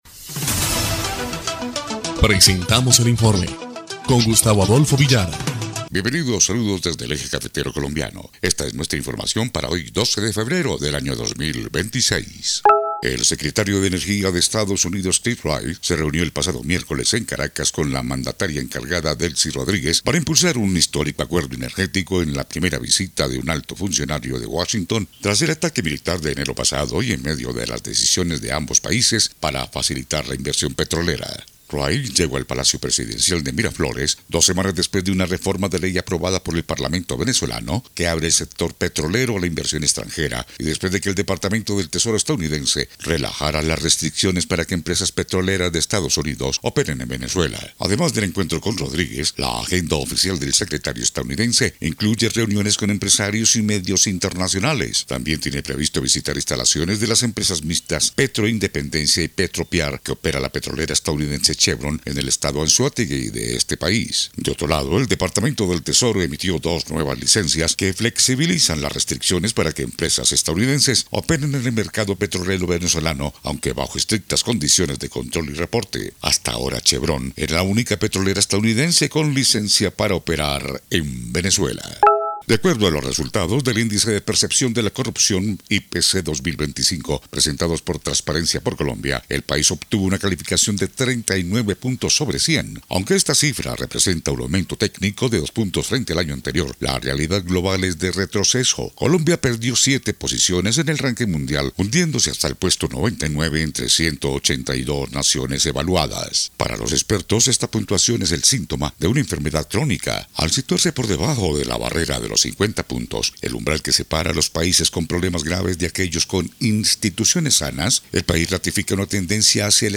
EL INFORME 2° Clip de Noticias del 12 de febrero de 2026